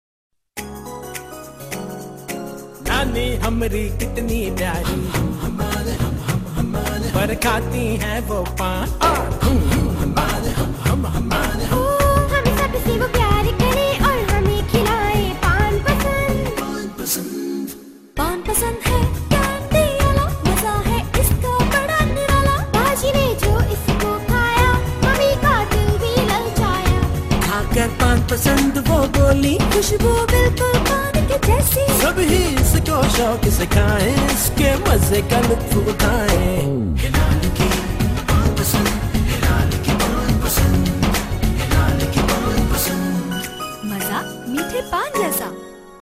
File Type : Tv confectionery ads